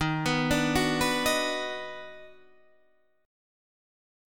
EbmM7#5 chord